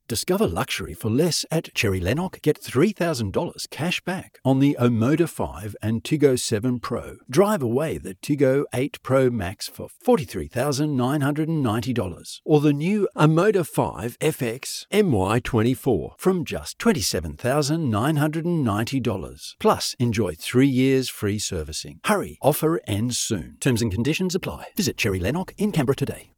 Male
English (Australian)
Adult (30-50), Older Sound (50+)
Silken baritone voice, for Documentary, Training videos and podcasts.
Radio Commercials